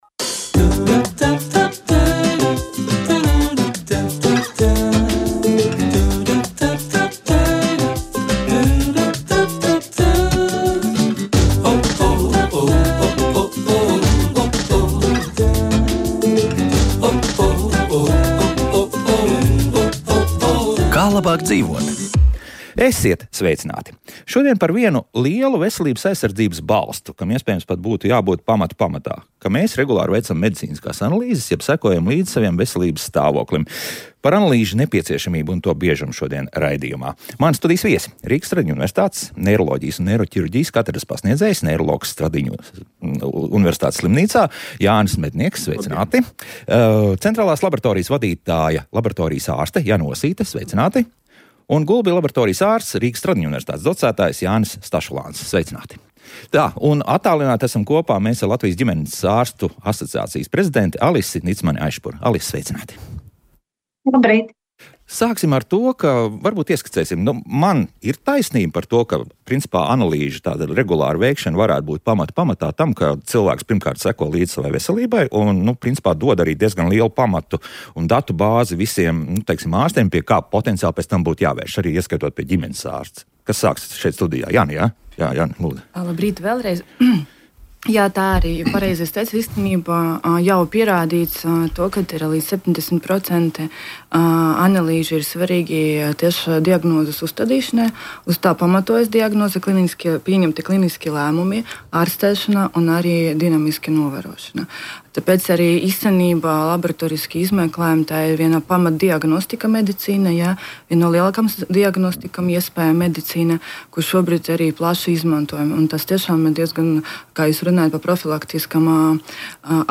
Par hormonu lomu un izplatītākajiem hormonālajiem traucējumiem saruna raidījumā Kā labāk dzīvot.